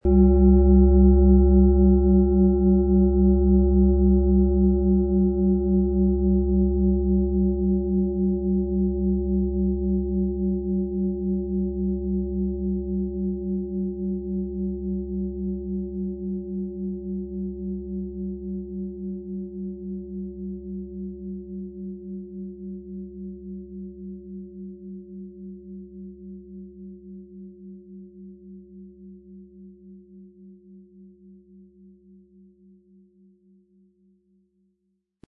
• Mittlerer Ton: Jupiter
Im Sound-Player - Jetzt reinhören können Sie den Original-Ton genau dieser Schale anhören.
PlanetentöneBiorythmus Körper & Jupiter
HerstellungIn Handarbeit getrieben
MaterialBronze